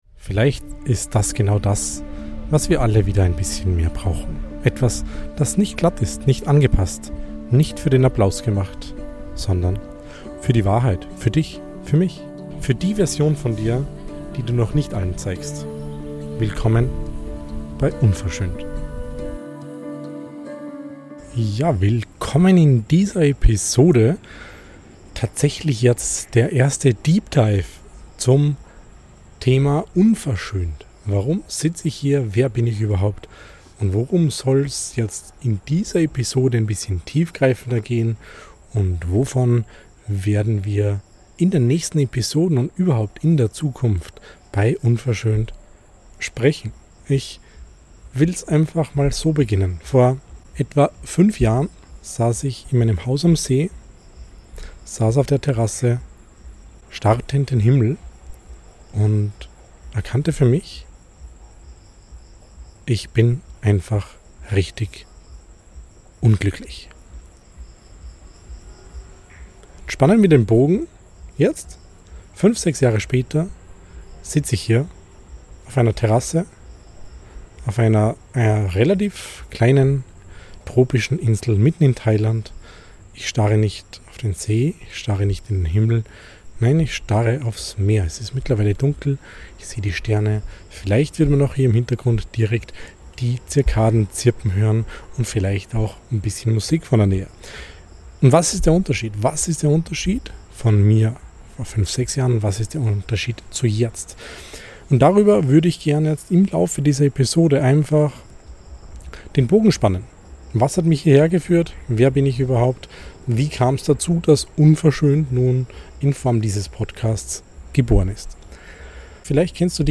Zwischen tropischem Inselrauschen und ehrlicher Reflexion spreche ich über Kontrolle, Freiheit, Selbstverantwortung und die Sehnsucht nach Echtheit. Eine Einladung, dich selbst zu hinterfragen und den Mut zu finden, deinen eigenen Weg zu gehen – unverschönt, roh und echt.